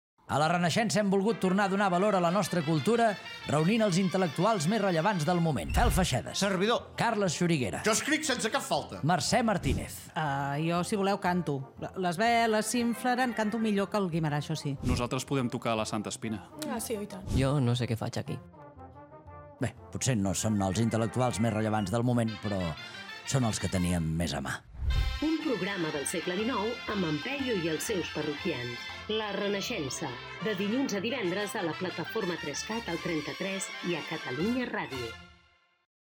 Promoció del programa abans de la seva estrena amb la presentació dels col·laboradors